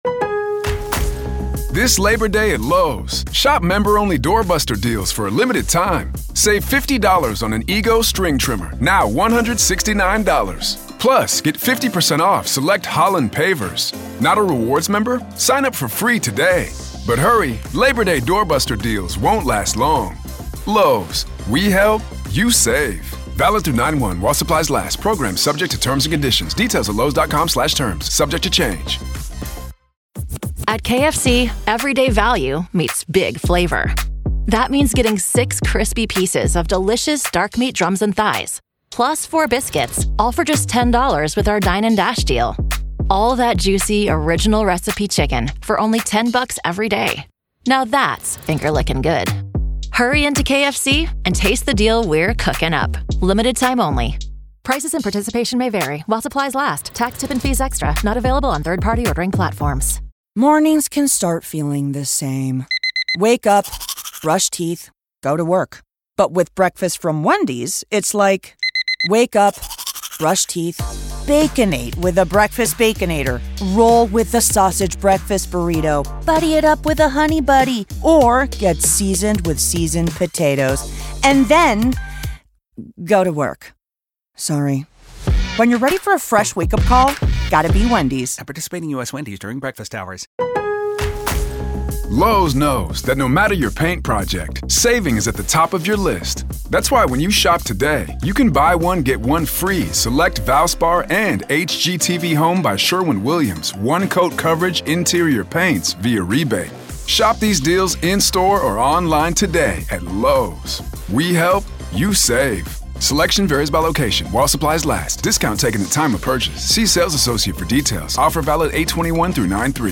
This is our continuing coverage of the Alex Murdaugh murder trial.